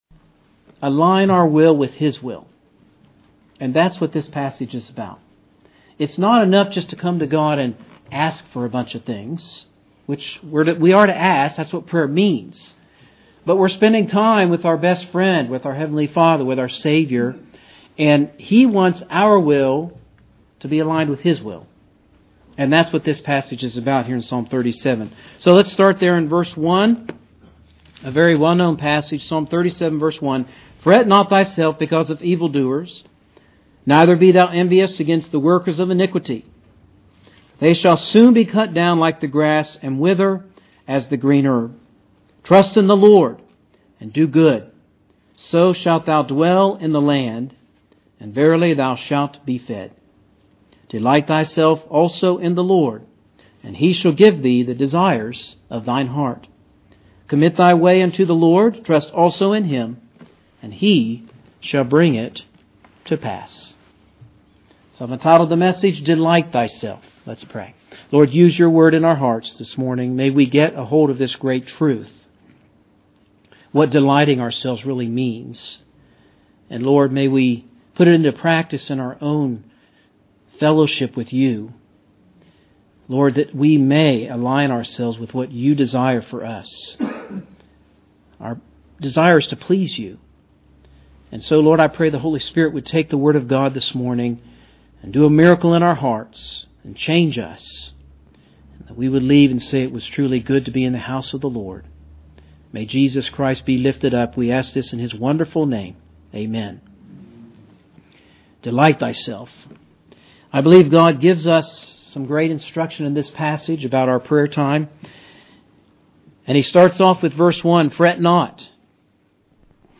Wednesday Evening
Sermon Details